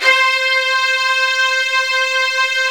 55u-va09-C4.aif